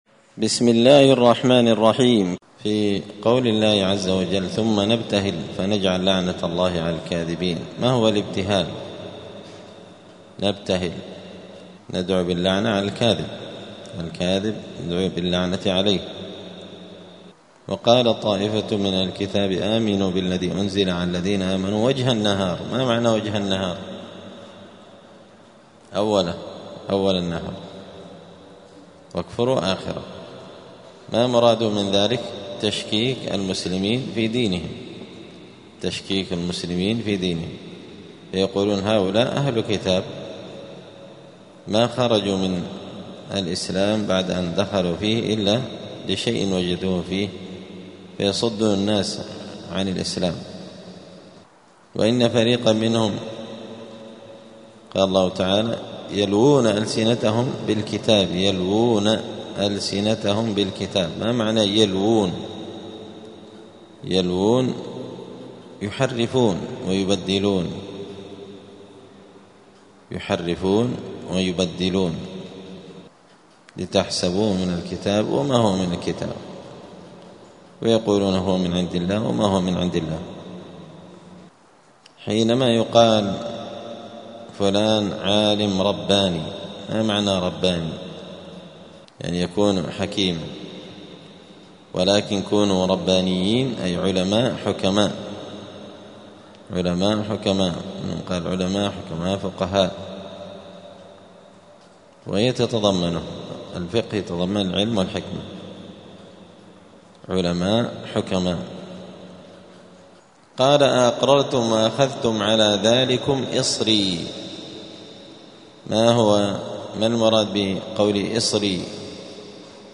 *مذاكرة لغريب القرآن في رمضان*
دار الحديث السلفية بمسجد الفرقان قشن المهرة اليمن